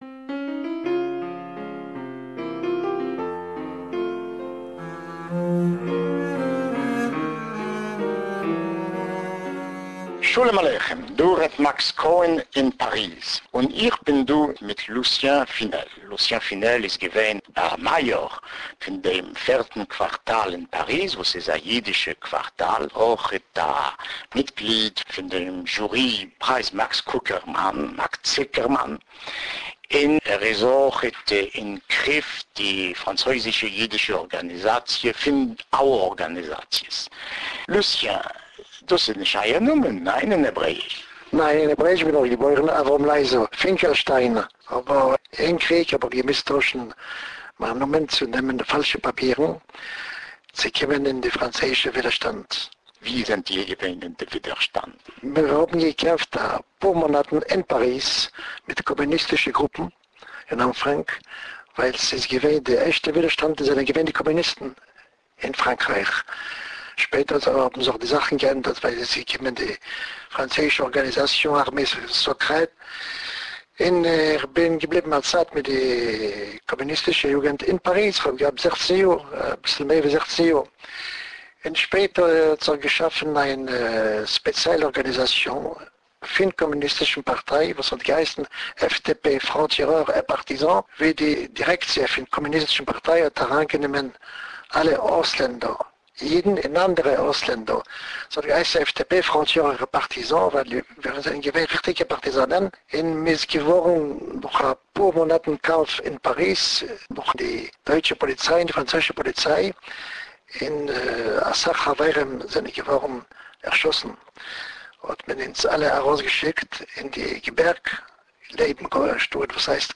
Interview de Lucien Finel ז״ל, ancien maire du IVe arrondissement à Paris, sur SBS Radio Yiddish, Melbourne, Australie, 19 avril 2009.
Interview de Lucien Finel